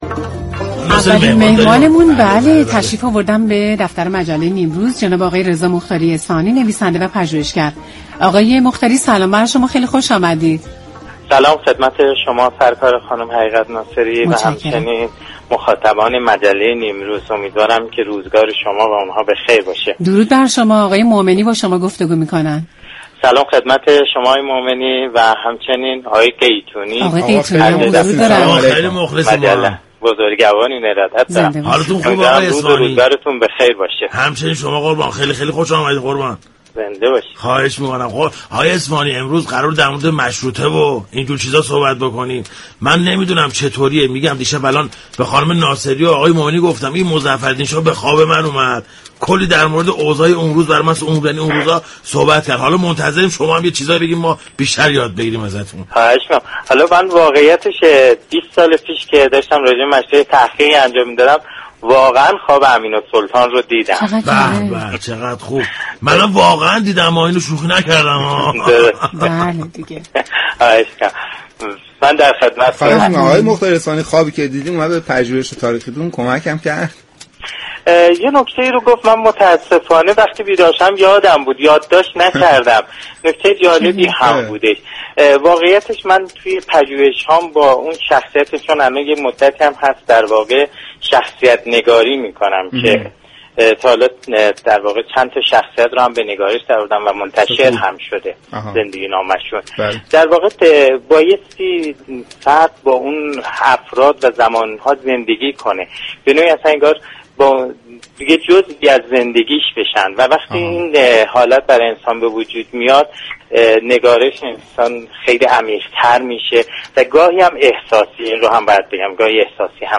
درباره ی نقش مردم در جنبش مشروطه گفتگو كرد .